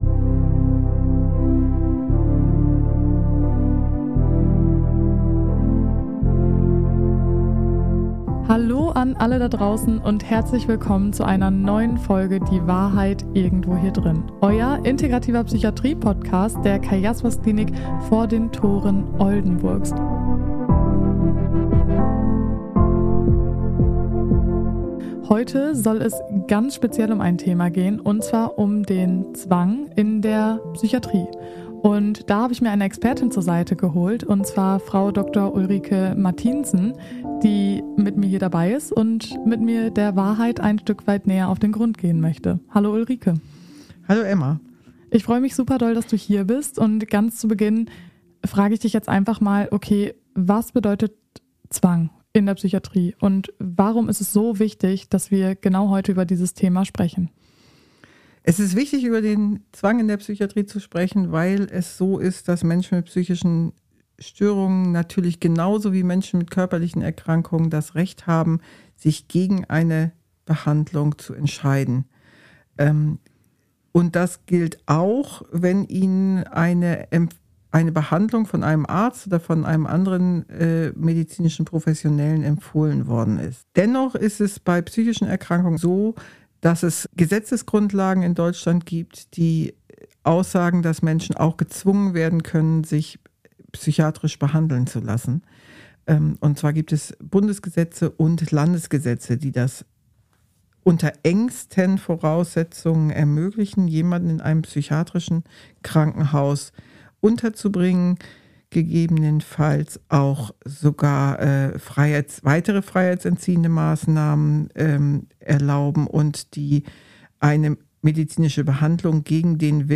#33 BEHANDLUNG UNTER ZWANG – Experten-Talk ~ Die Wahrheit Irgendwo Hier Drinnen Podcast